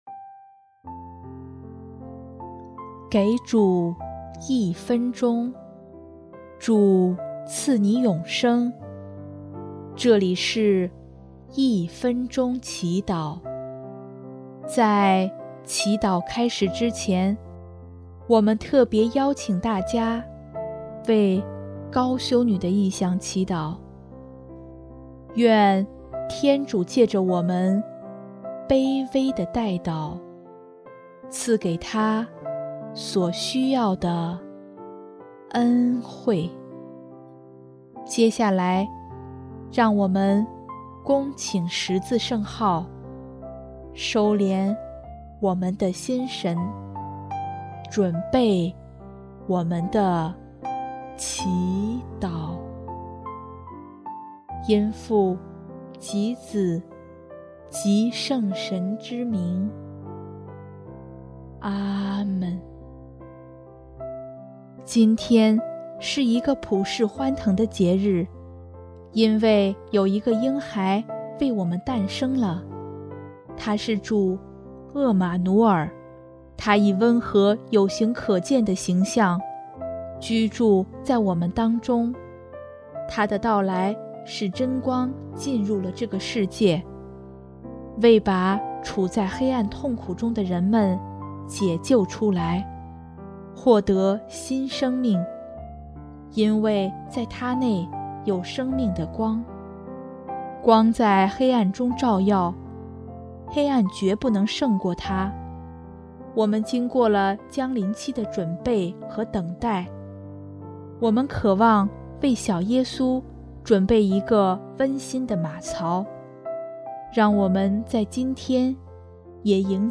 音乐： 第四届华语圣歌大赛三等奖《圣诞祝福》